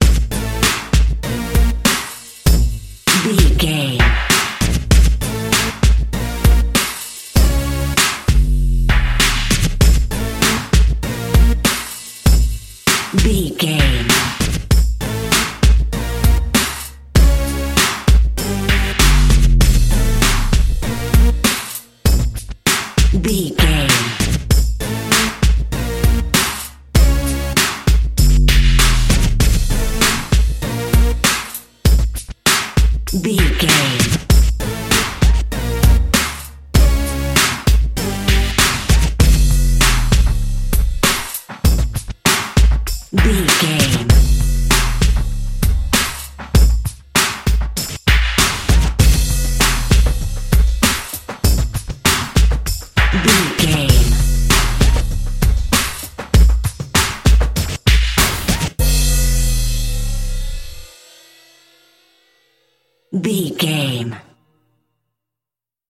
Ionian/Major
drum machine
synthesiser